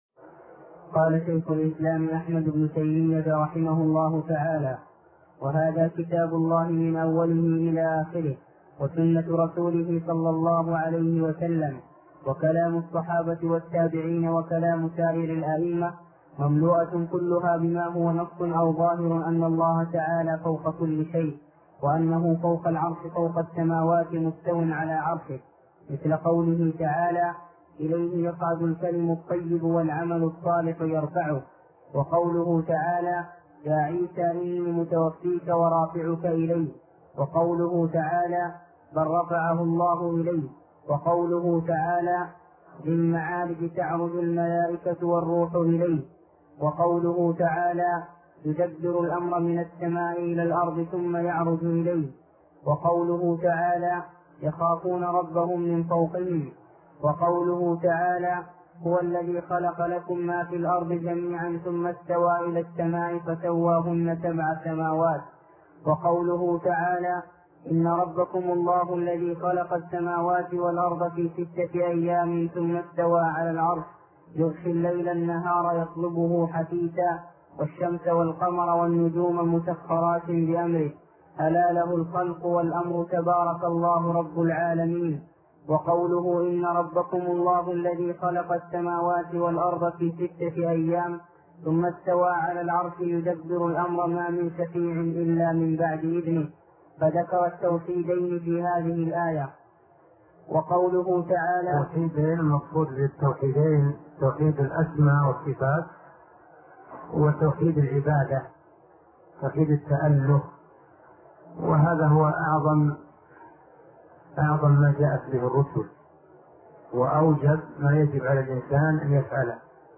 الدرس ( 138) شرح فتح المجيد شرح كتاب التوحيد